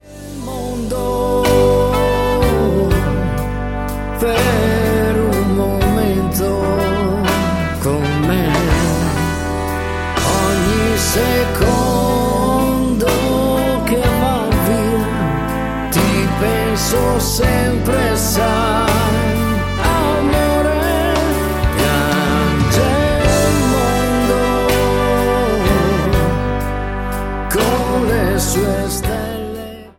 SLOW  (03,47)